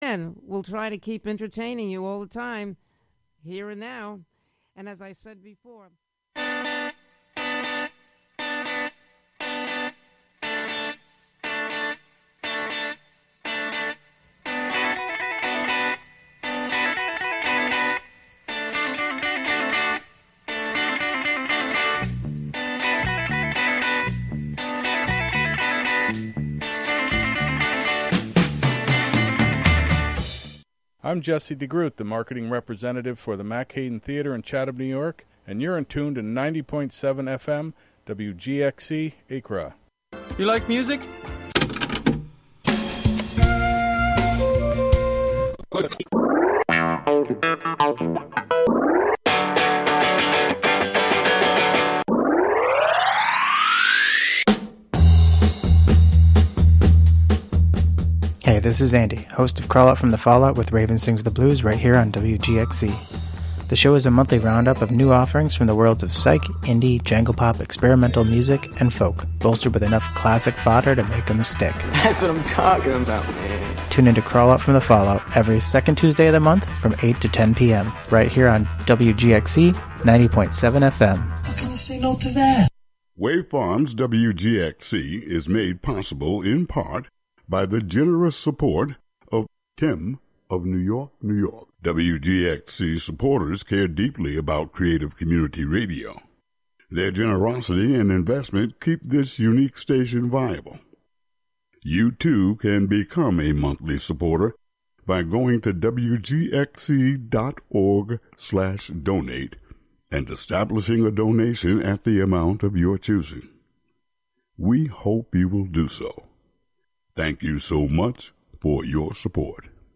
Tune into the “Wednesday Afternoon Show” for national news, interviews with community leaders and personalities, reports on cultural issues, a rundown of public meetings and local and regional events, weather updates, and more about and for the community, made by volunteers in the community.